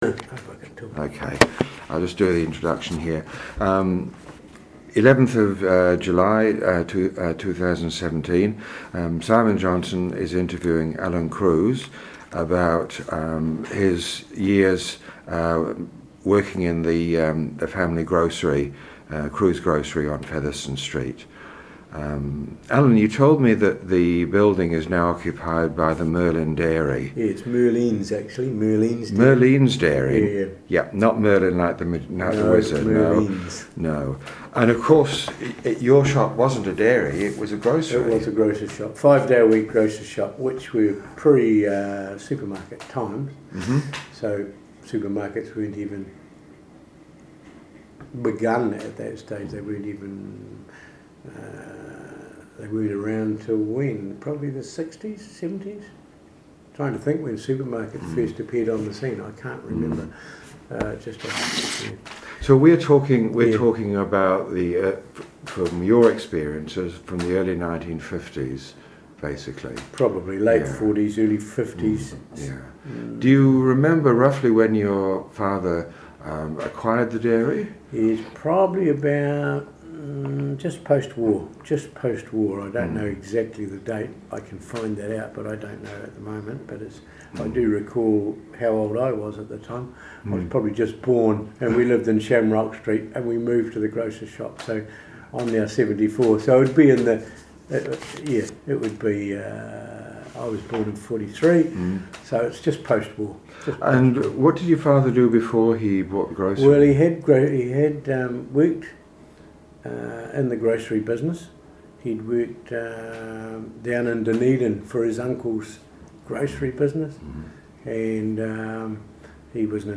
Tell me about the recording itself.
Creation Place Palmerston North